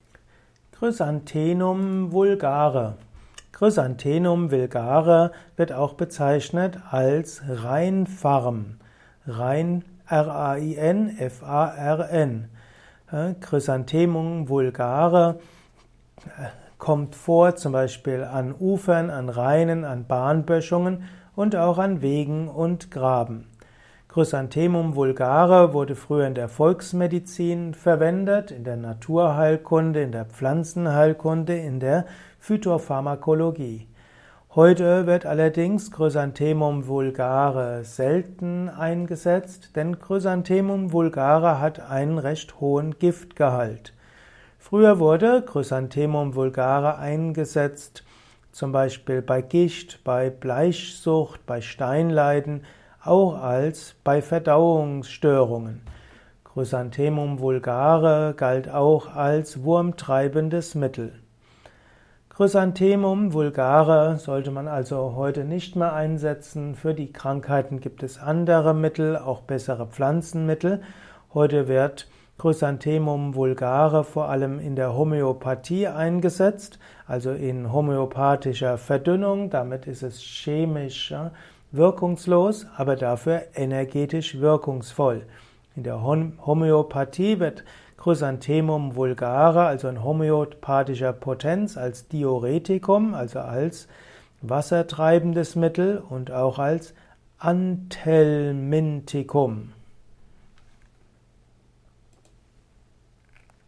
Audiovortrag zum Thema Chrysanthemum_Vulgare
Er ist ursprünglich aufgenommen als Diktat für einen Lexikonbeitrag im Yoga Wiki Bewusst Leben Lexikon.